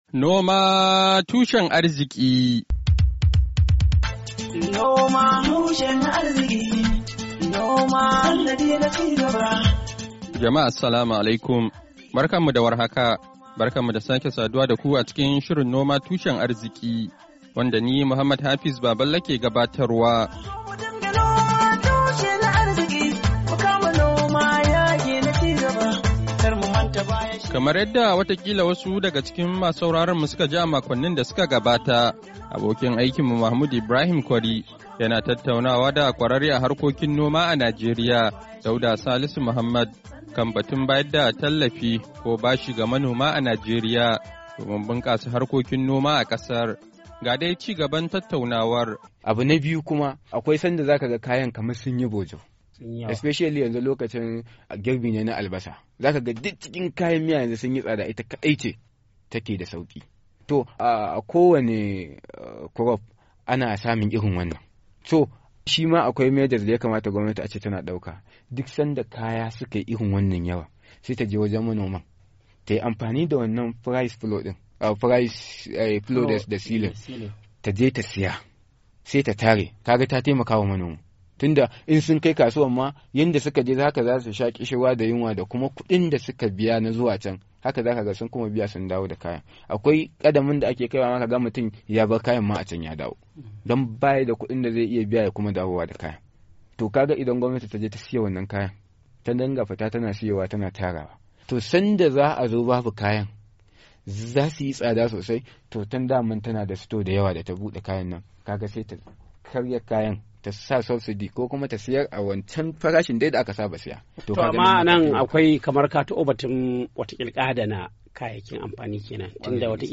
NOMA TUSHEN ARZIKI: Hira Da Kwararre Kan Harkokin Noma A Kan Batun Bunkasa Noma A Najeriya "7'15" - Copy.mp3